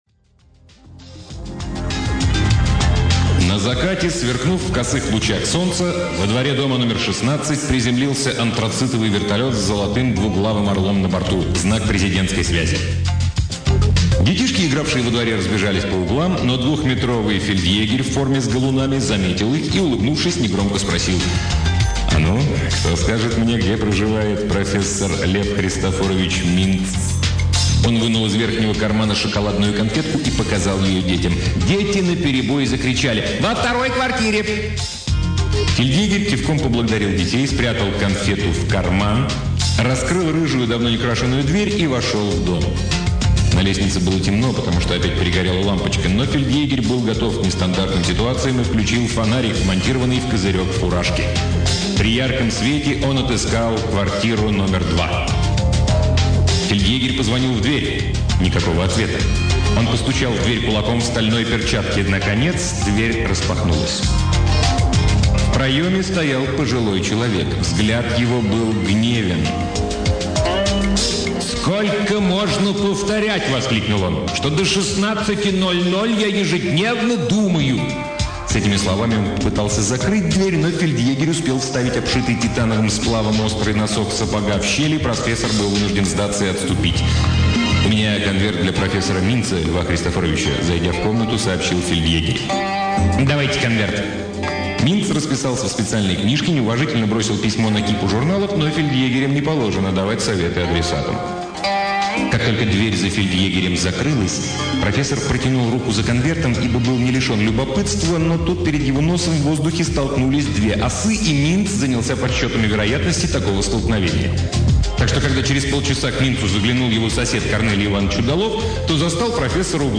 Аудиокнига Кир Булычев — Звезды зовут